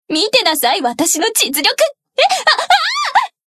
贡献 ） 分类:蔚蓝档案语音 协议:Copyright 您不可以覆盖此文件。